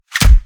PUNCH_DESIGNED_HEAVY_23.wav